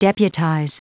Prononciation du mot deputize en anglais (fichier audio)
Prononciation du mot : deputize
deputize.wav